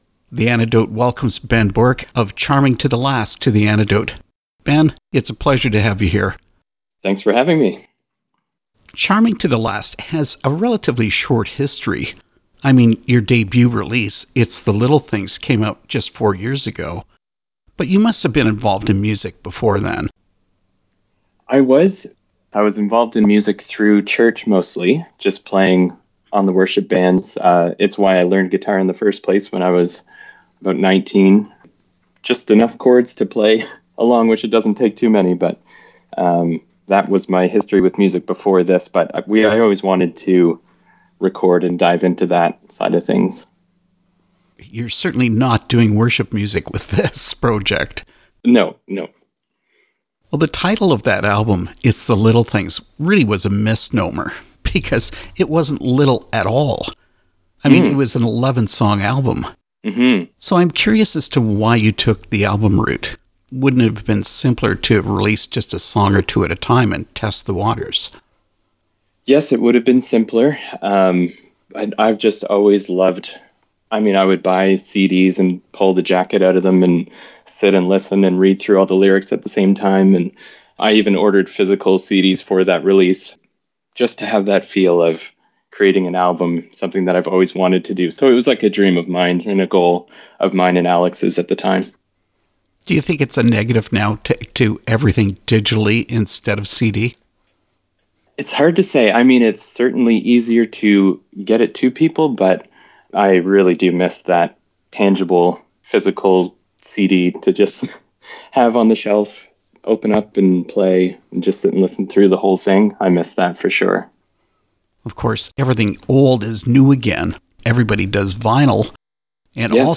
Interview with Charming to the Last
charming-to-the-last-interview.mp3